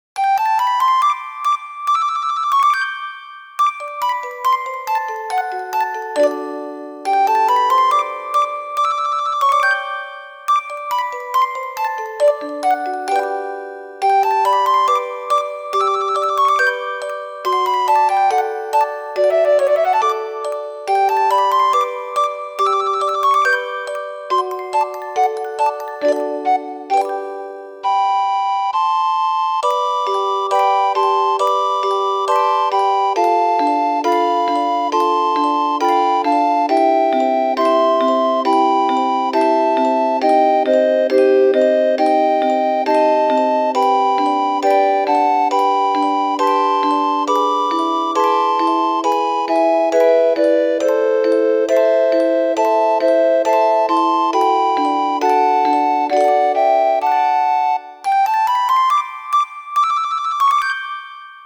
ogg(L) 癒し しっとり かわいい
しっとりと響く一曲。